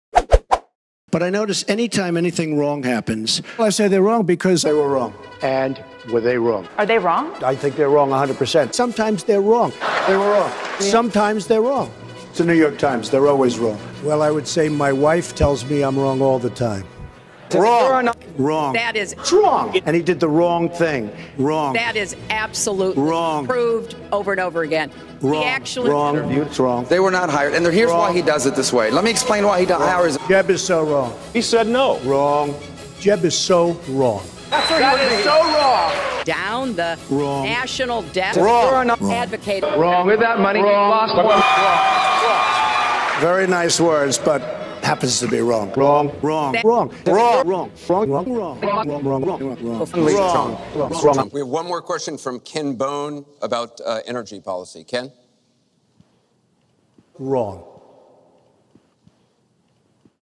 Trump Saying 'WRONG!' Supercut.f140.m4a